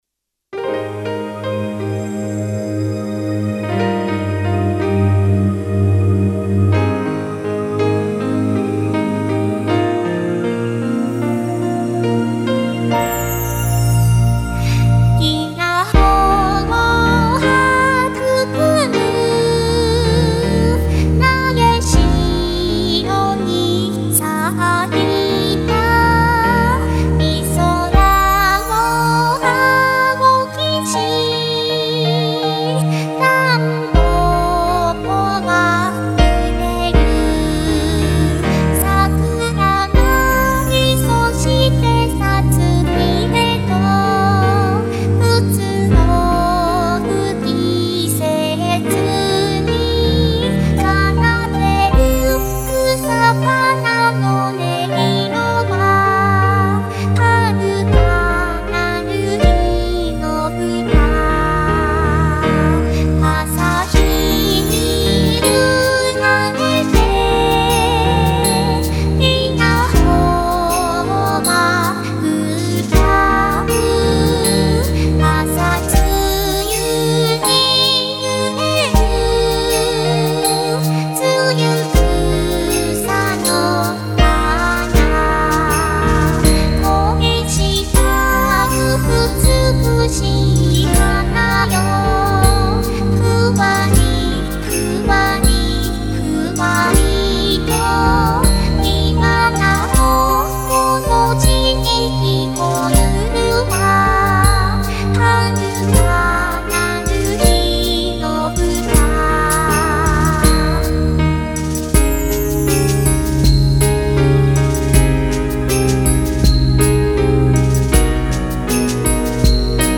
「日本の四季」をテーマにした和風癒し系楽曲です。
寝る前にオススメです。